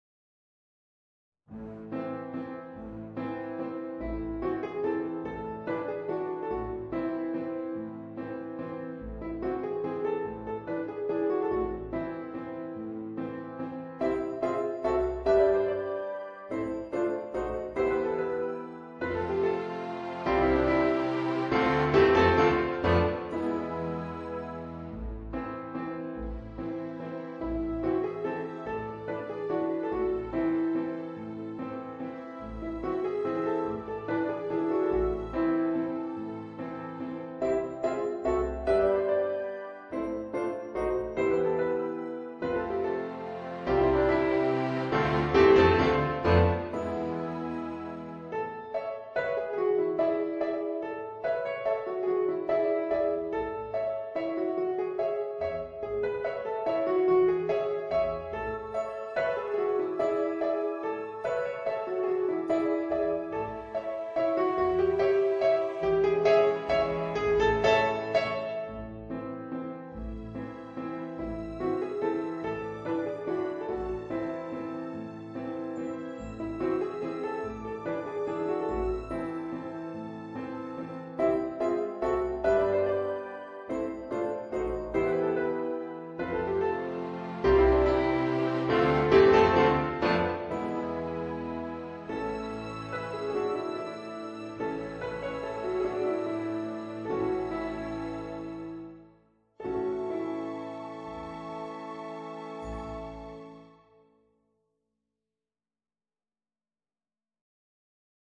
Voicing: Piano and Orchestra